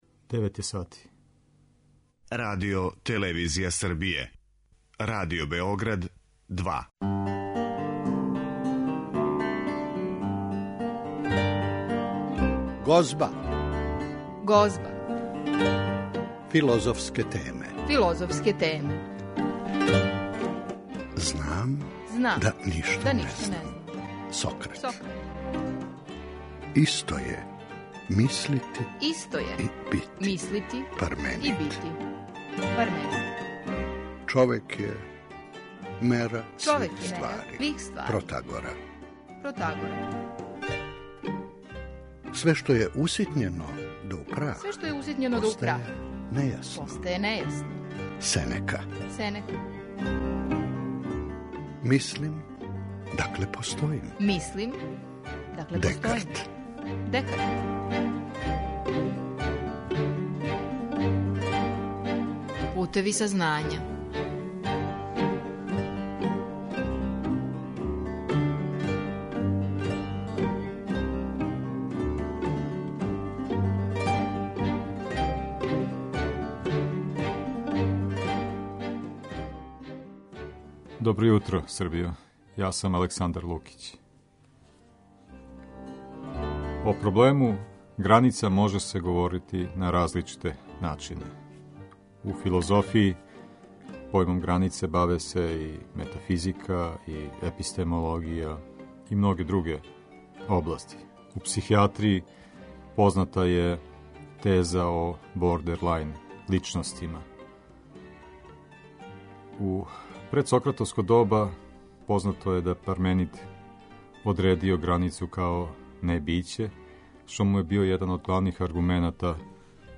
Емисија се бави филозофским темама